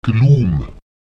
Lautsprecher glúm [gluùm] langsam